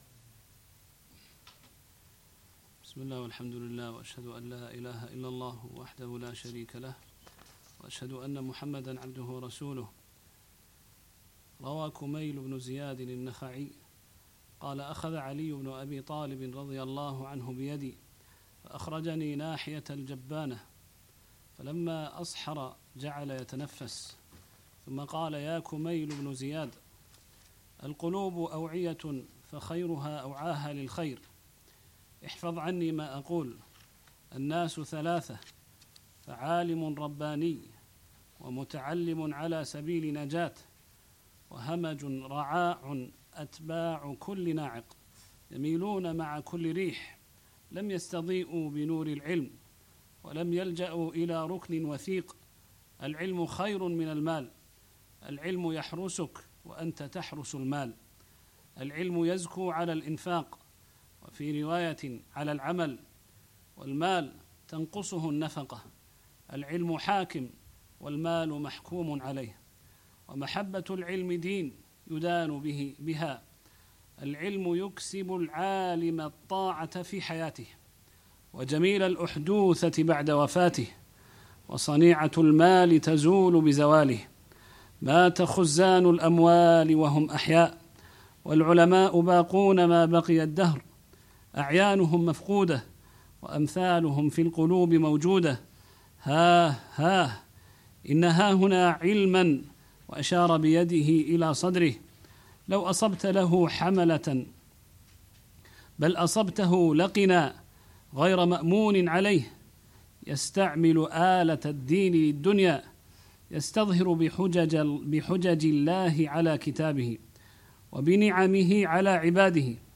الدروس والمحاضرات